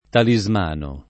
[ tali @ m # no ]